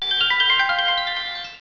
Mega DV Manager is especially annoying because of its musical interface. Everything makes a noise when you move the mouse cursor over it.
That gets old, I think, faster than anything else in human history has ever gotten old.